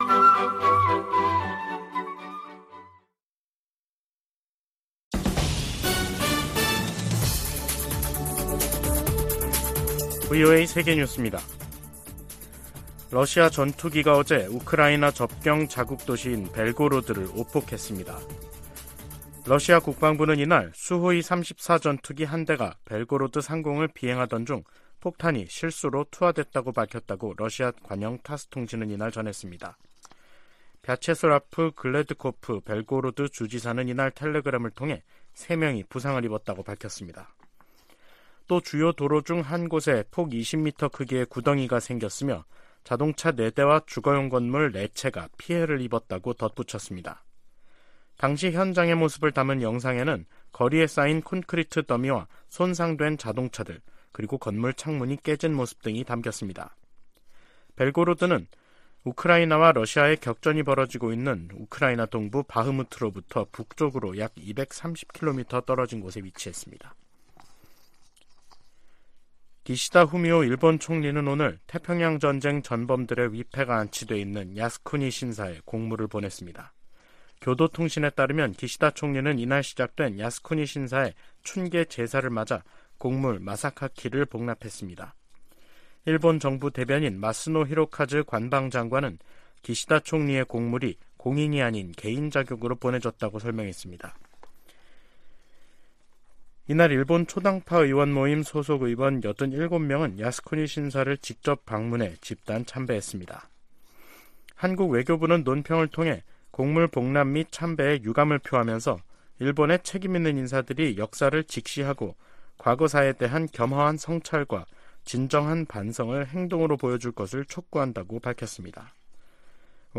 VOA 한국어 간판 뉴스 프로그램 '뉴스 투데이', 2023년 4월 21일 2부 방송입니다. 백악관은 윤석열 한국 대통령의 국빈 방문 기간 중 북한·중국 문제가 논의될 것이라고 밝혔습니다.